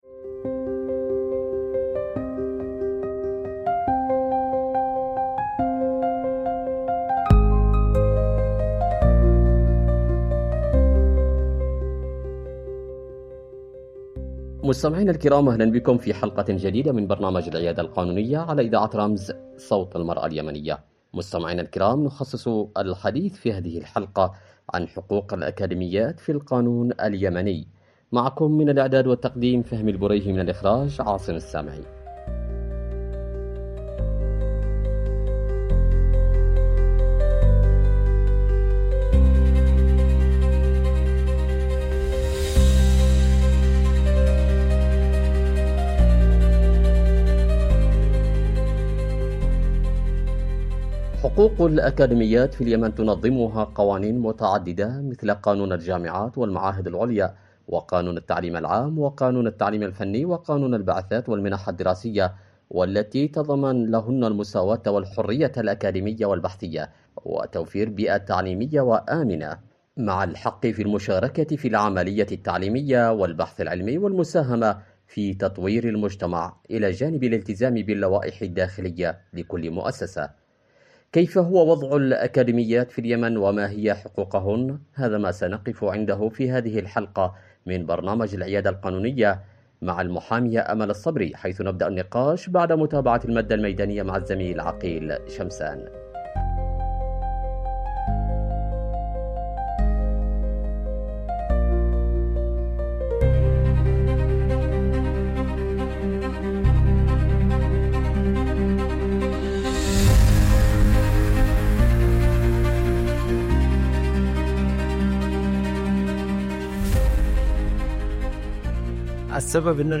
عبر إذاعة رمز